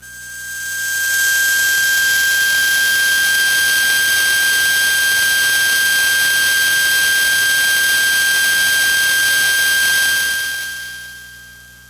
描述：通过Modular Sample从模拟合成器采样的单音。
Tag: F6 MIDI音符-90 Sequntial-MAX 合成器 单票据 多重采样